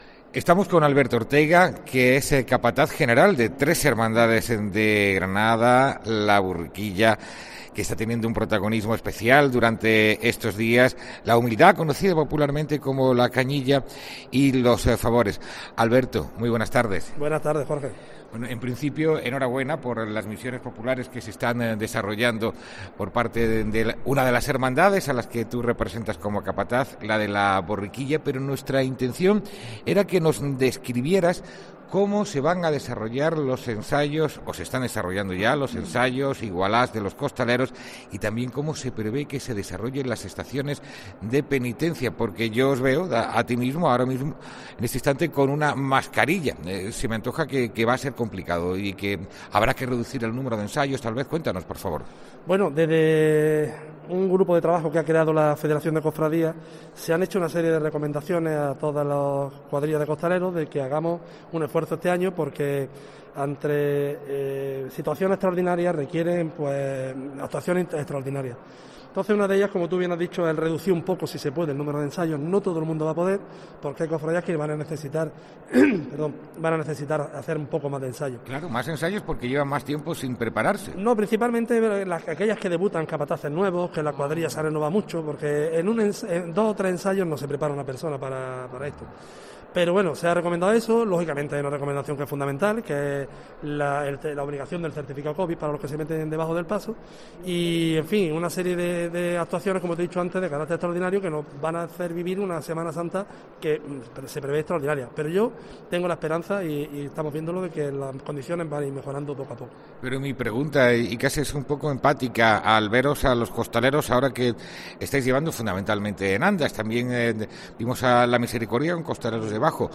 COSTALEROS